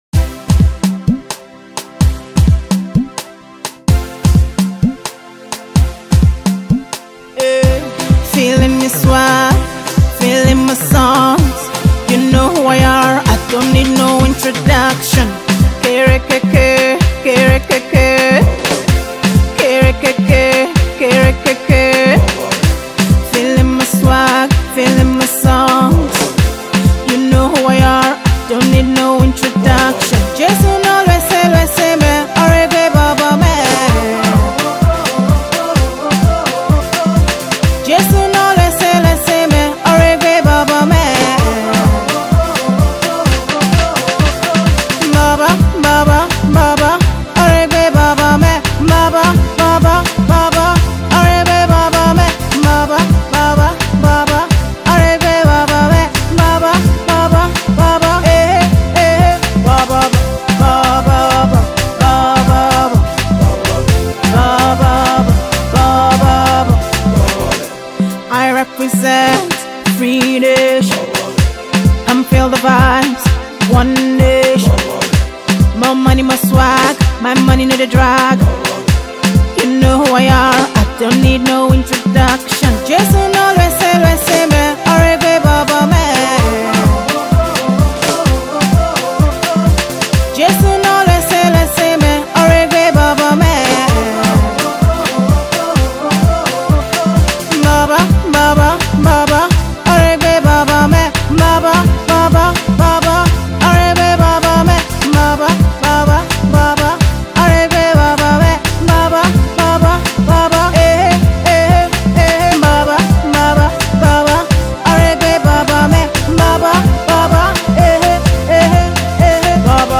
Christian & Gospel SongsNigerian Gospel Songs
Genre:Gospel